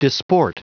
Prononciation du mot disport en anglais (fichier audio)
Prononciation du mot : disport